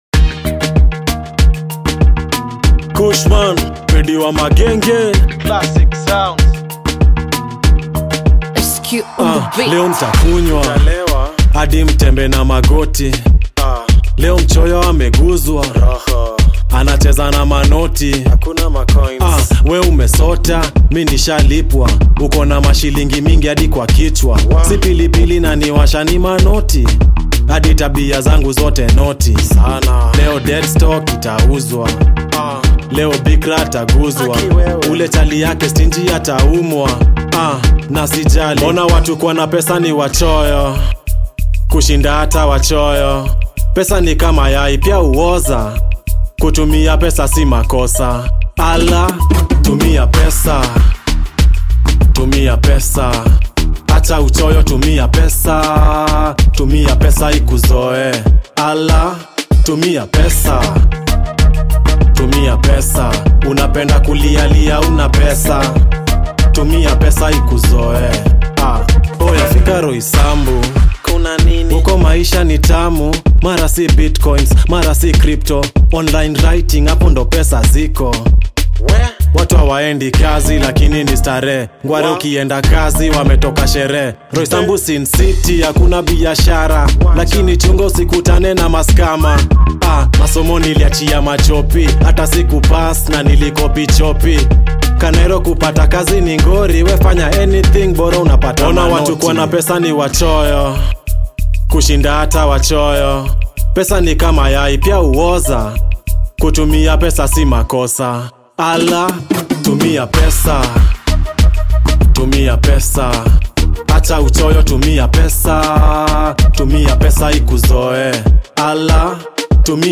Kenyan genge singer and songwriter